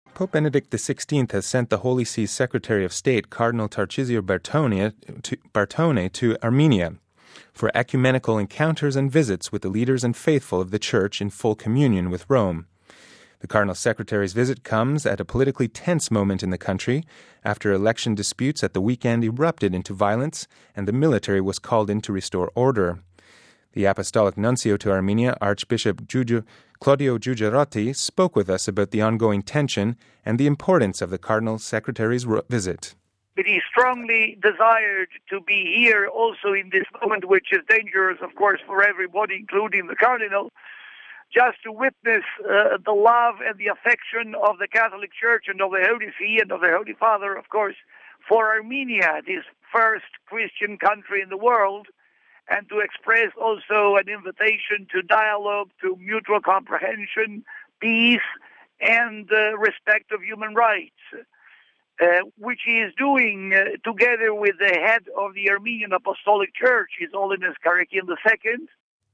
The Cardinal-Secretary’s visit comes at a politically tense moment in the country, after election disputes at the week-end erupted into violence and the military was called in to restore order. The Apostolic Nuncio to Armenia, Archbishop Claudio Gugerotti spoke with us about the ongoing tension and the importance of the Cardinal Secretary’s visit…